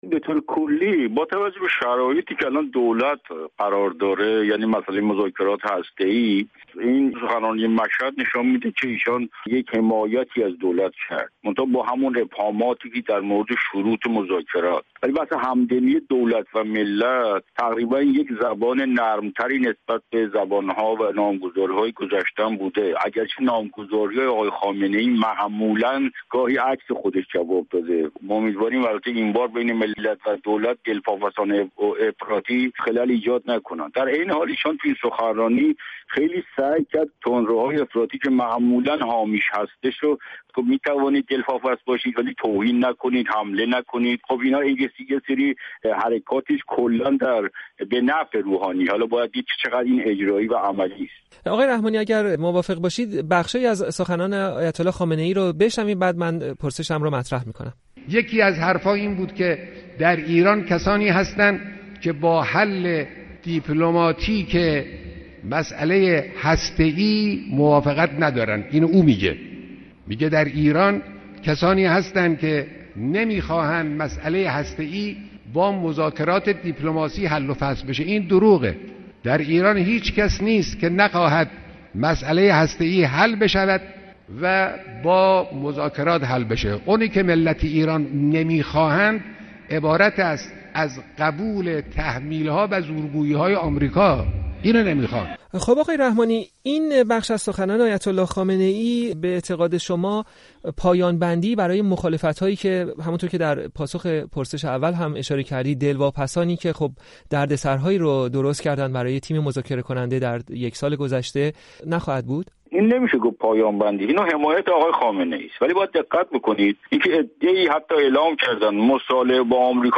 گفت‌وکوی